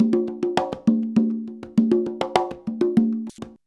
133CONGA04-R.wav